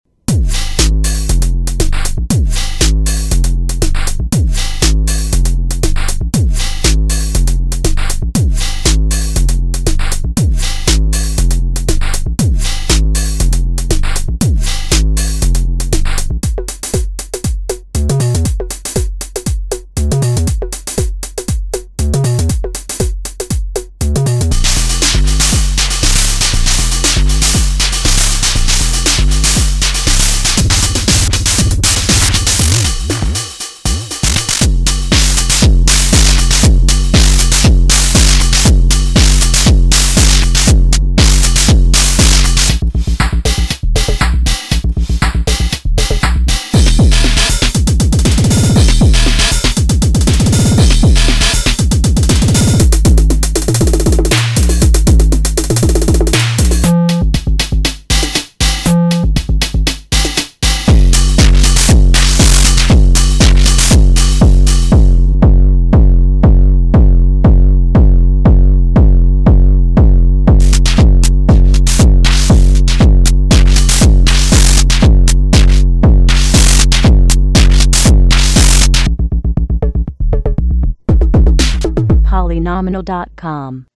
drum machine
- PCM HIHATS is a sampled digital PCM channel at 8 bits resolution.
- ANALOG BASS DRUM and SNARE have both analog circuits.
demoAUDIO DEMO
mix pattern 1